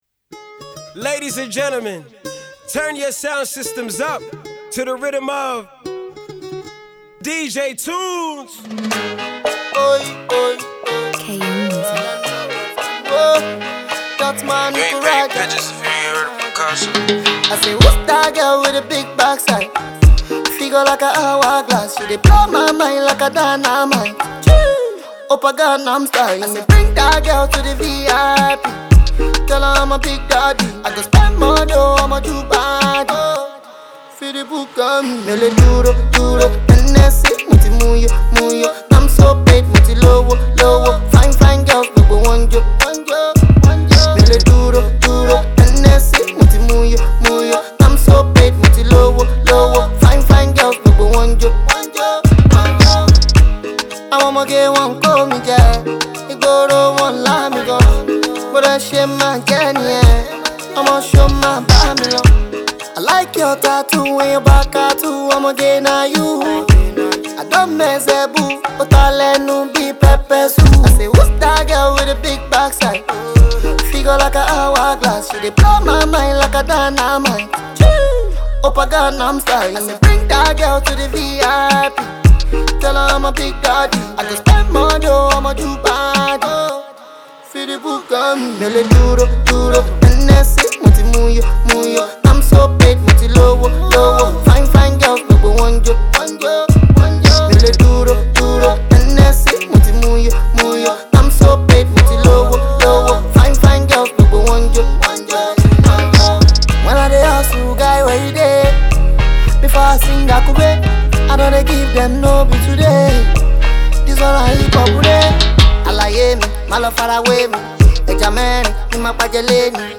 The song is a fun, light-hearted one